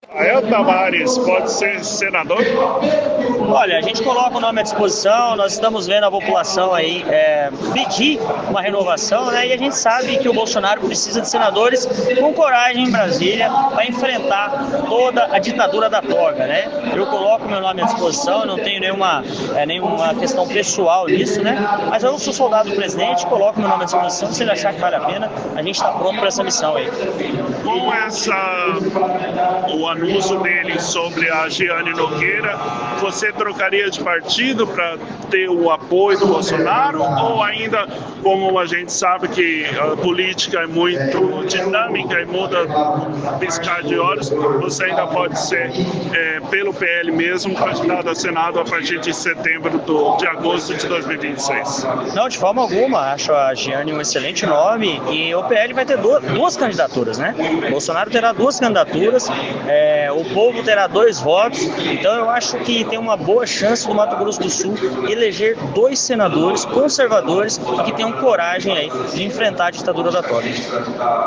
Em conversa com o portal O Contribuinte na manhã desta terça-feira, 18, o vereador Rafael Tavares (PL), se colocou a disposição do partido para disputar uma cadeira ao Senado em 2026.
Audio-entrevista-Rafael-Tavares-18-03.m4a